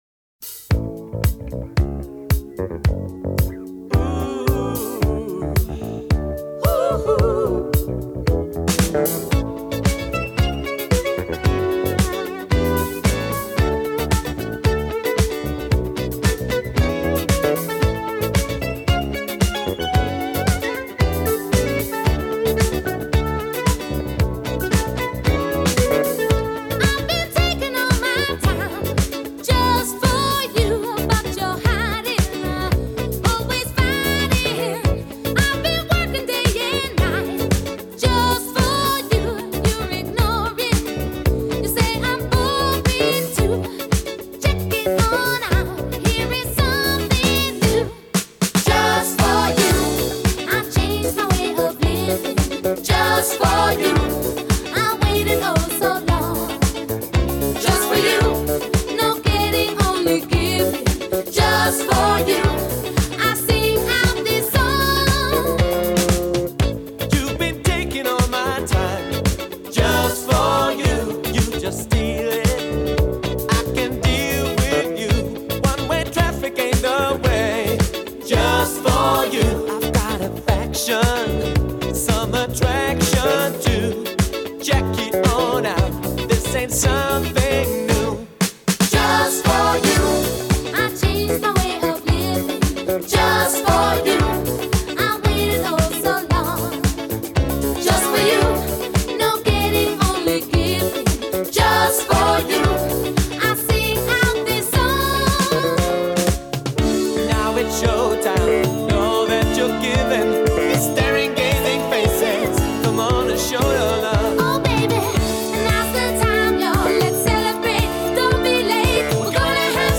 música disco